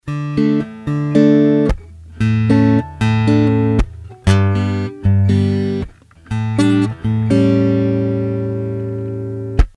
Я сыграл короткий отрывок (длиной от 10 до 15 секунд) тремя различными способами в мой рекордер Fostex Compact Flash:
2) Через предусилитель Taylor K-4
Гитара, K4 и BenzBenz были настроены на "плоскую", без каких-либо эффектов.
Исходя из того, что я услышал через мой компьютер, K4 (2) был более полным, а стрит (1) в порядке.